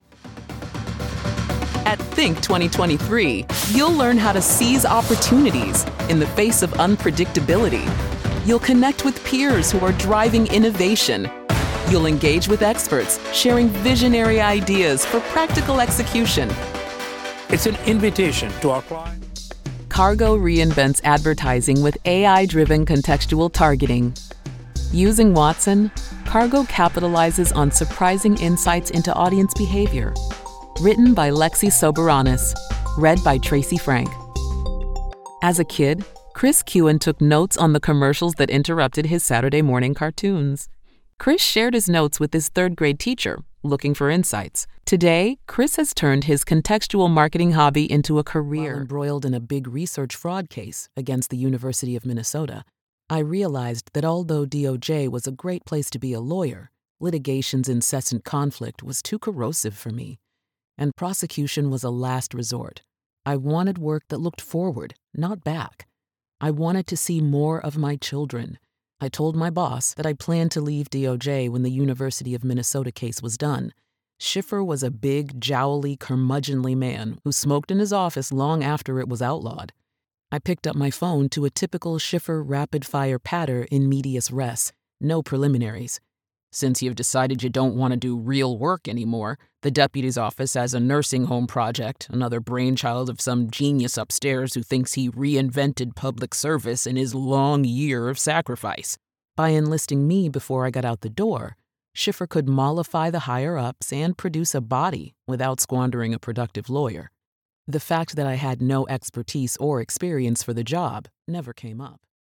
Knowledgeable and Trustworthy
Narration Reel
Narration Voiceover
Voice actress with warm, rich tones conveying strength and wisdom.